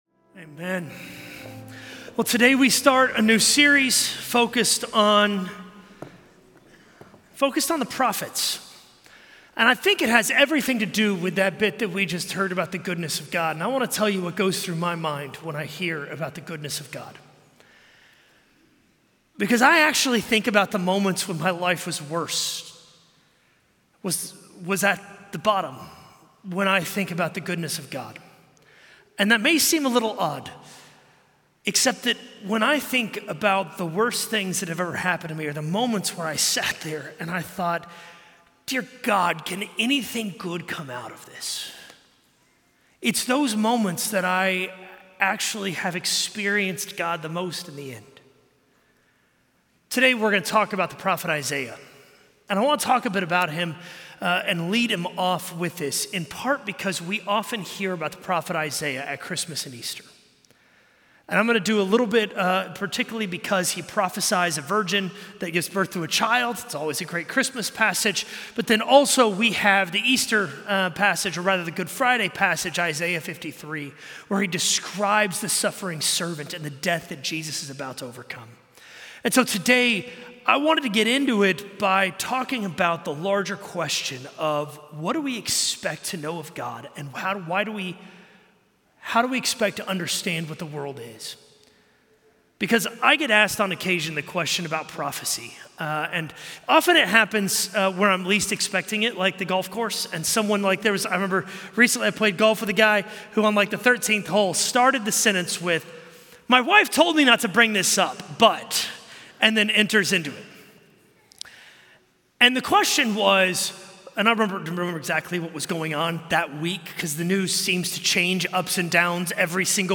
A message from the series "Prophets."